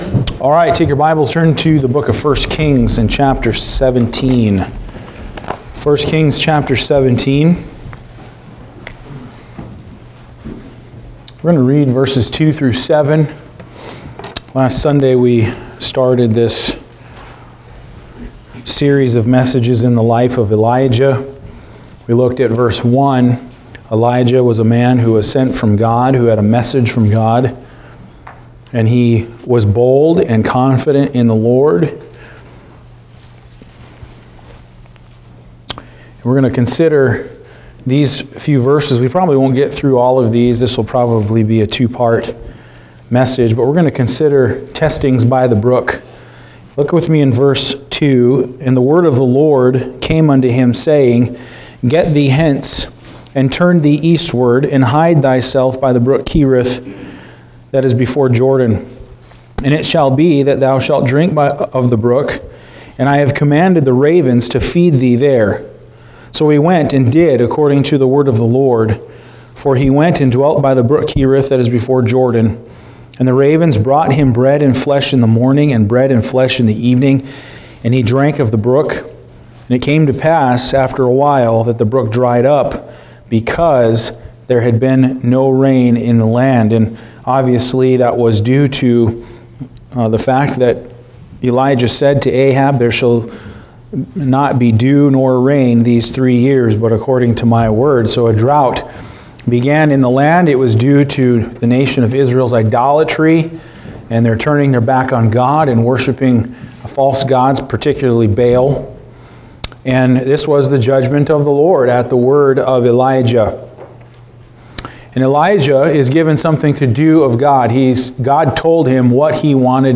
Service Type: Sunday Afternoon